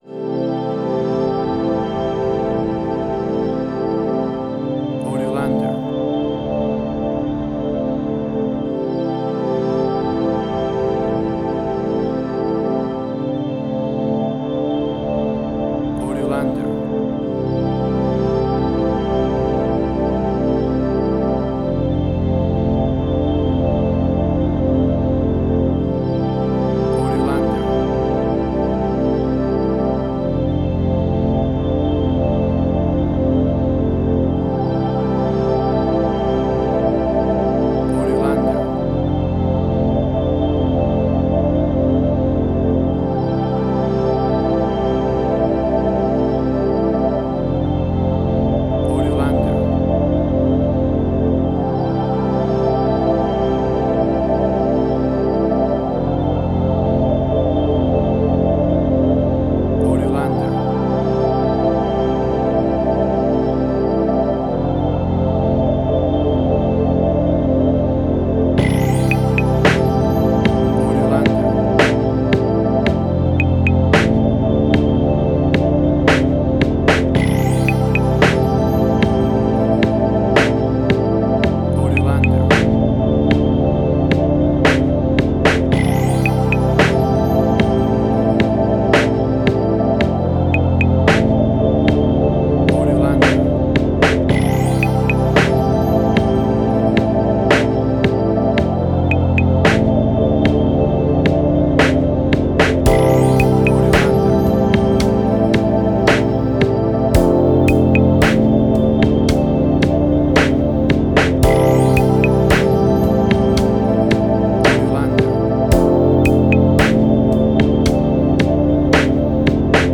A cool and relaxed evening.
WAV Sample Rate: 16-Bit stereo, 44.1 kHz